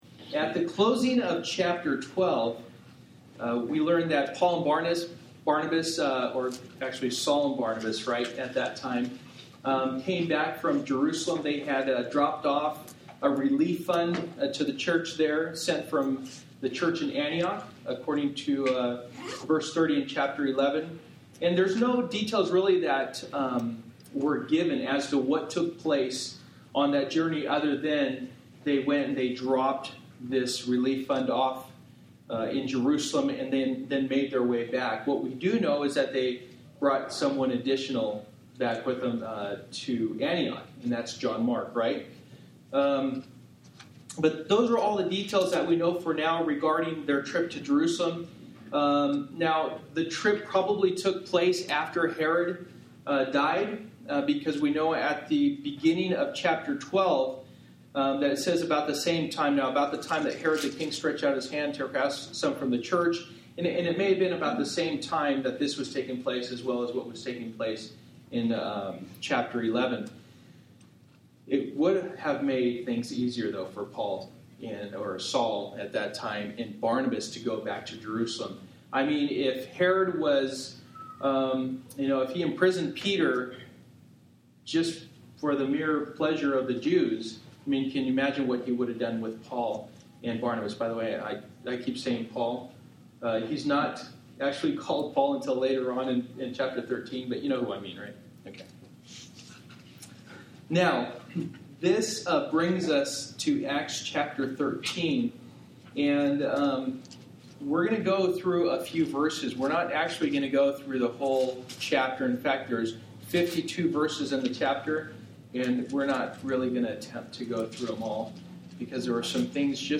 Acts 13:1-7 Service: Wednesday Night %todo_render% « The Answer to Hope